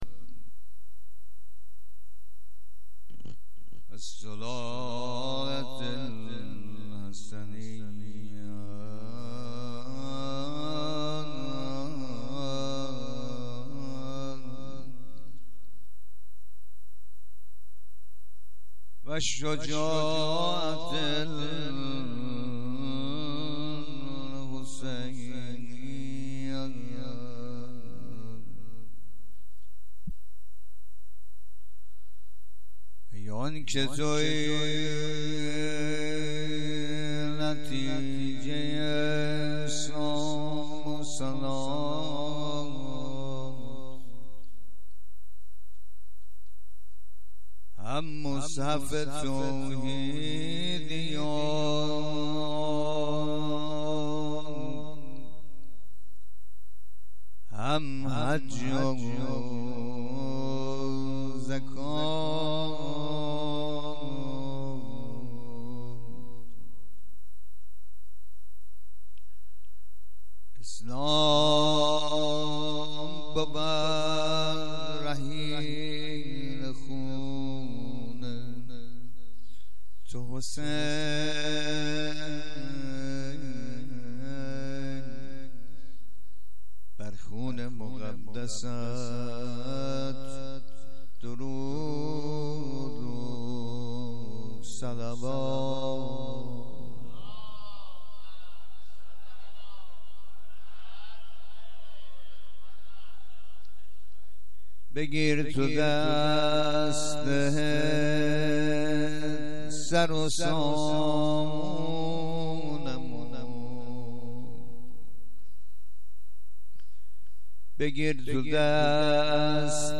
مداحی شب ششم محرم سال 1396{ ذاکرین
هیئت فاطمه الزهرا (سلام الله علیها) (دربندسریها)